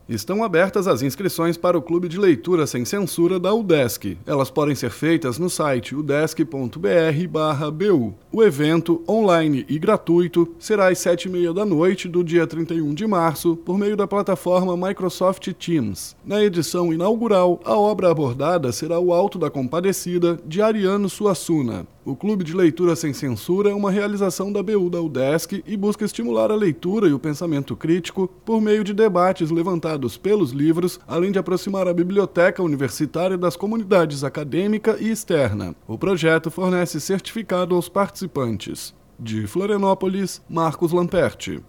BOLETIM – Clube de Leitura da Udesc inicia programação anual com encontro em 31 de março